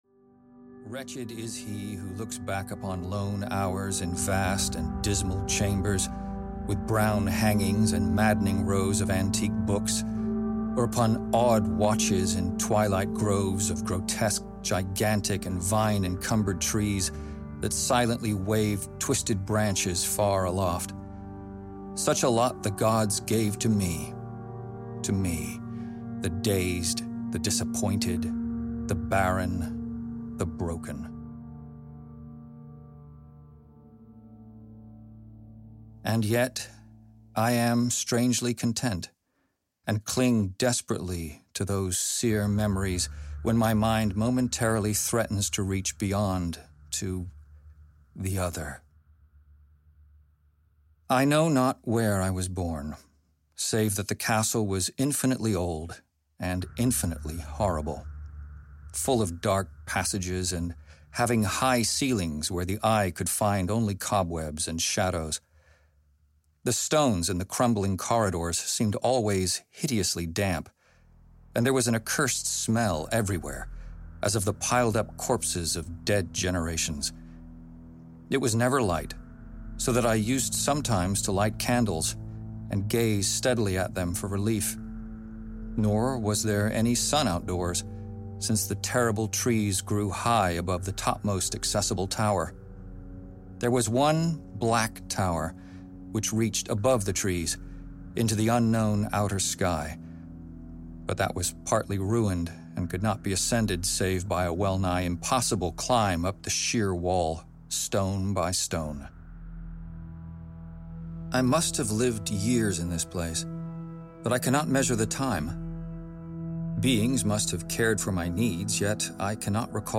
Audio knihaLonging
The atmosphere is emphasized by actors’ performances, music, and the graphics on the cover.